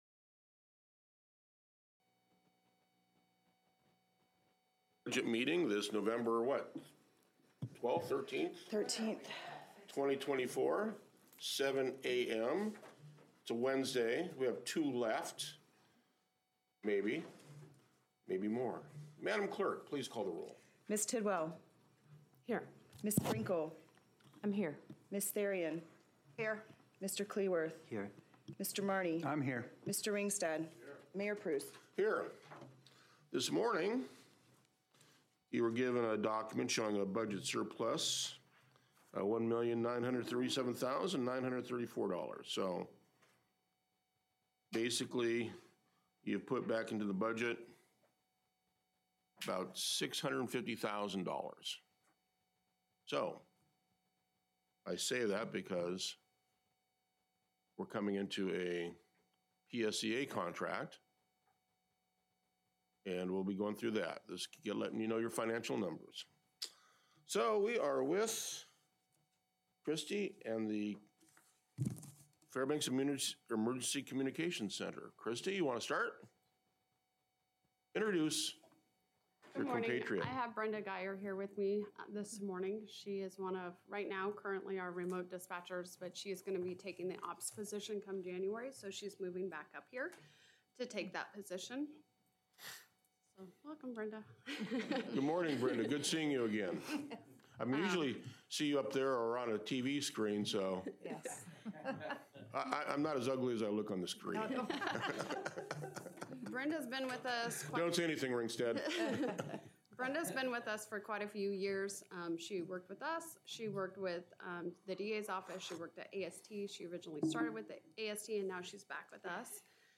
Council Budget Meeting